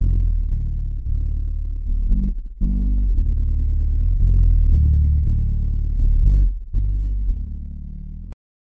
BASS LOOPS - PAGE 1 2 3 4 5
dub.rm